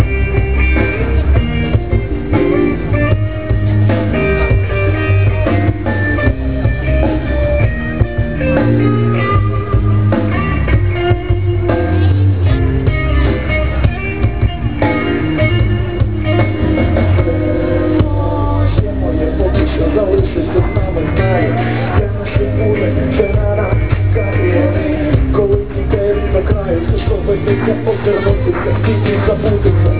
Little dude rocking out After wandering through all of the places I’ve already shown pictures of we walked to the “bottom” of the center (furthest from the opera house) where they had a live concert going on. This little guy was rocking out to the tunes.